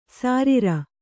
♪ sārita